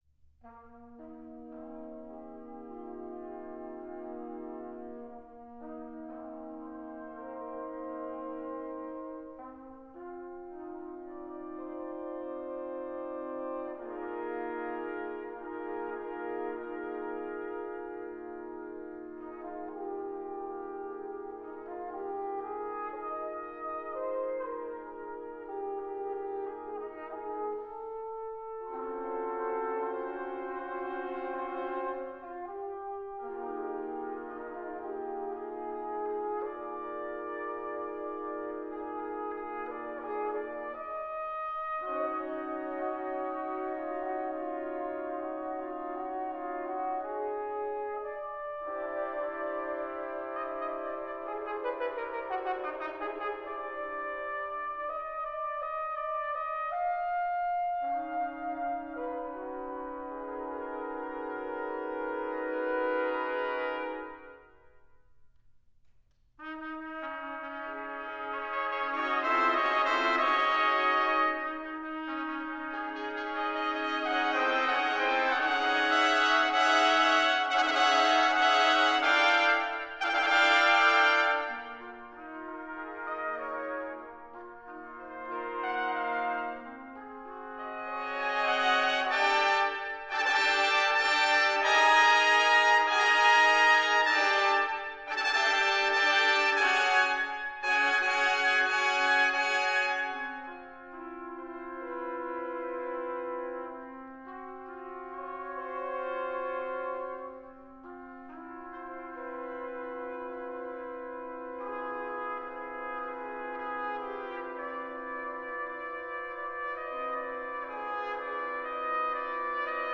Number of Trumpets: 6
Key: Eb Major concert